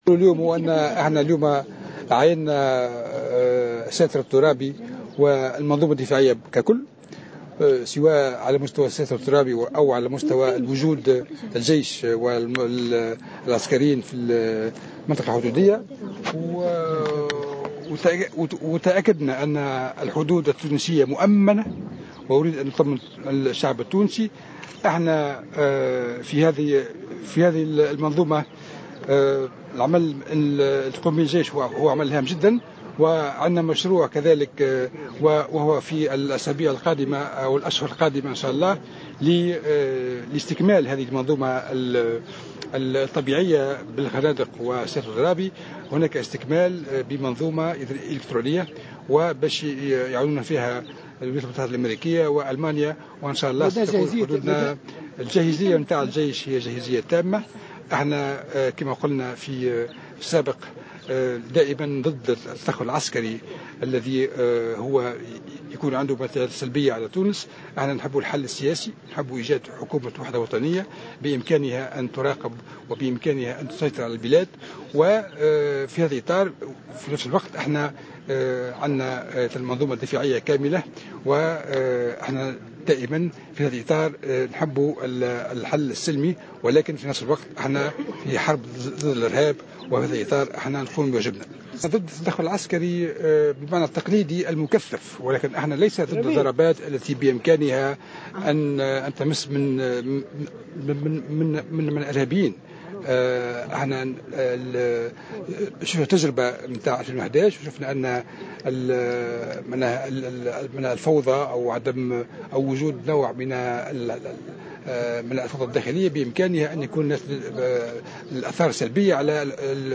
Le ministre de la Défense nationale, Farhat Horchani, a assuré, dans une déclaration à Jawhara Fm, ce vendredi 26 février 2016, en marge d’une visite qu’il a effectué au passage frontalier de Ras Jedir, que les frontières de la Tunisie avec la Libye sont sécurisées, au niveau du fossé de séparation ainsi que pour le système défensif en entier.